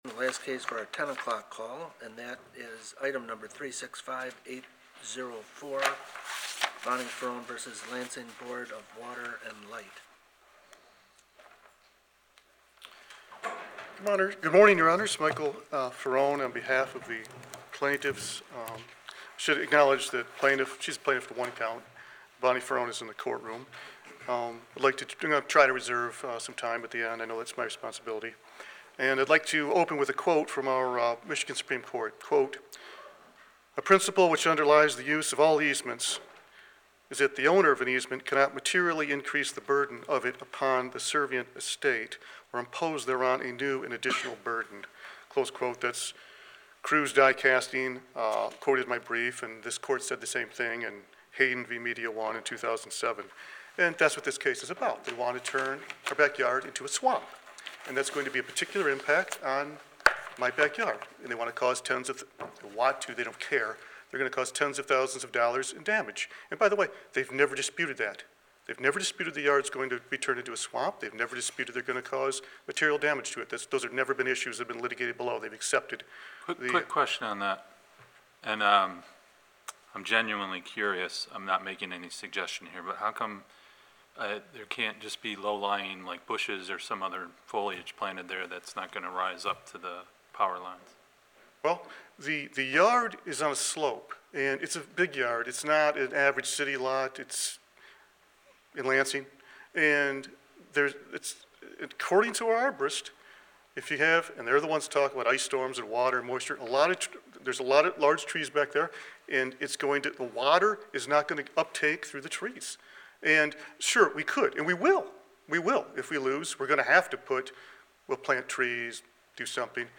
Oral argument was held on January 15, 2025, and can be heard below.